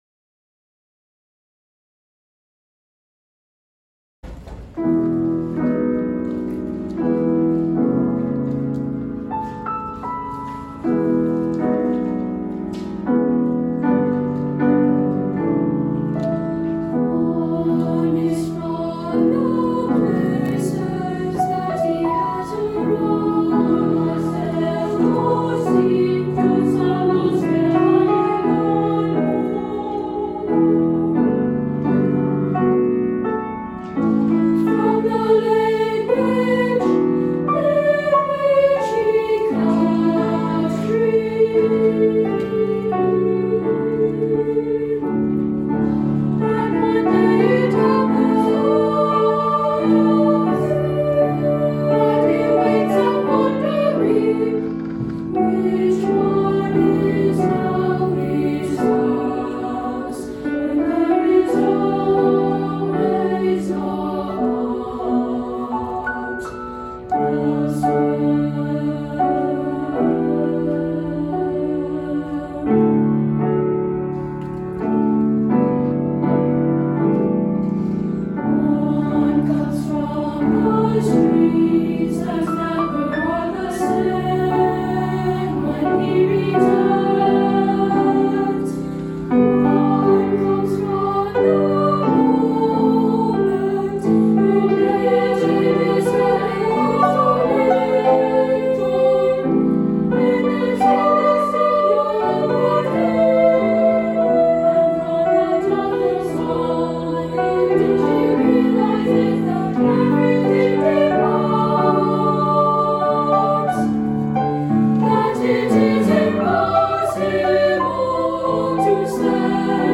rehearsal performance
A bi-lingual exploration of language, home, and identity.
2-part treble, piano